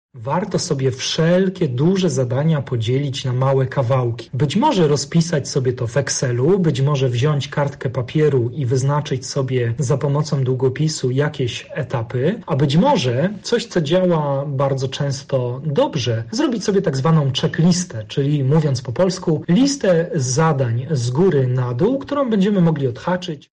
trener mentalny.